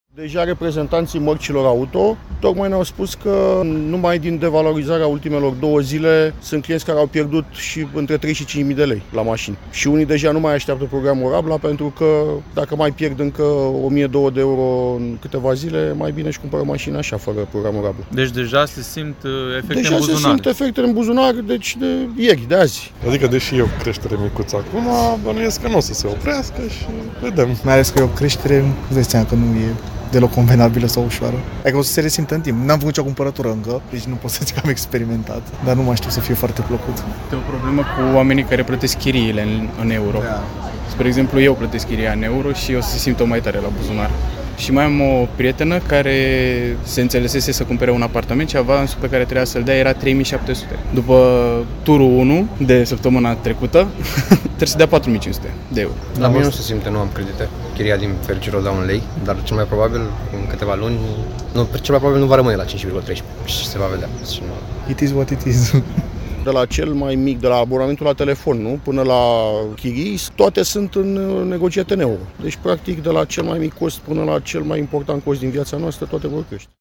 08mai-13-Voxuri-crestere-euro-LUNG.mp3